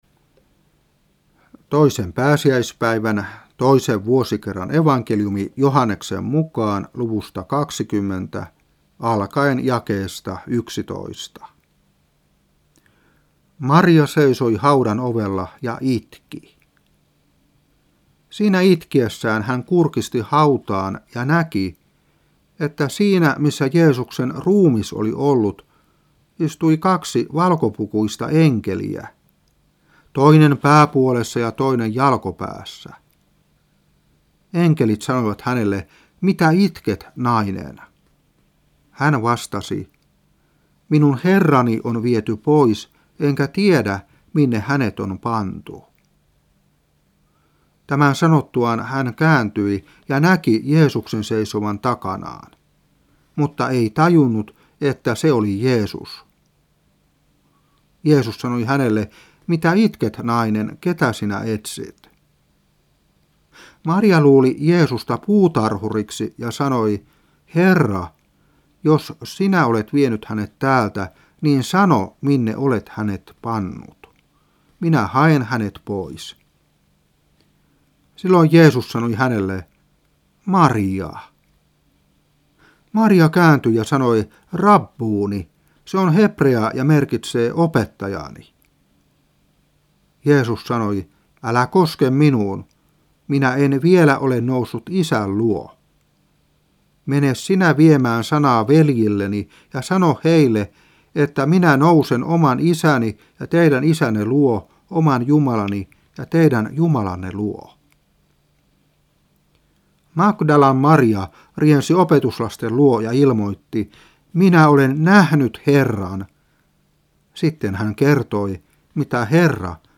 Saarna 1994-4. Joh.20:11-18.